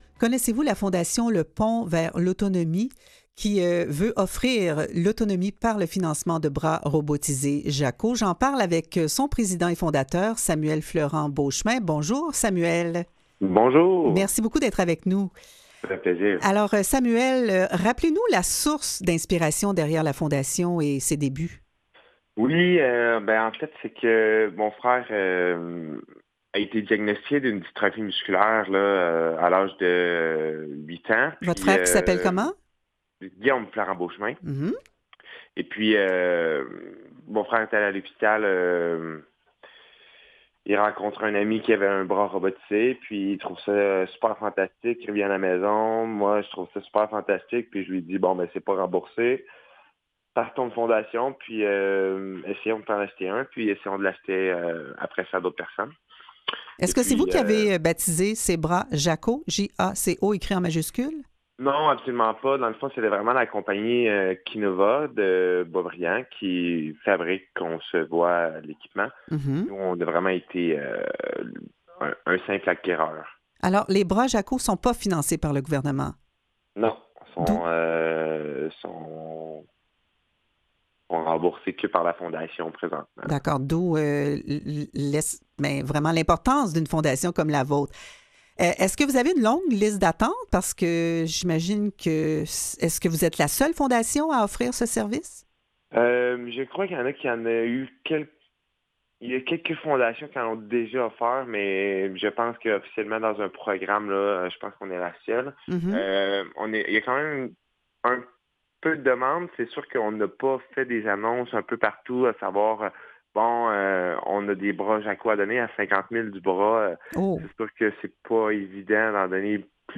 En entrevue: